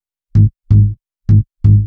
Index of /VEE/VEE2 Melody Kits 128BPM